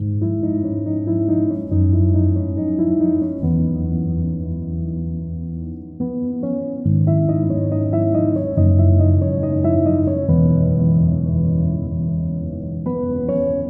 ZZZ钢琴
描述：让我昏昏欲睡
Tag: 140 bpm Hip Hop Loops Piano Loops 2.31 MB wav Key : Unknown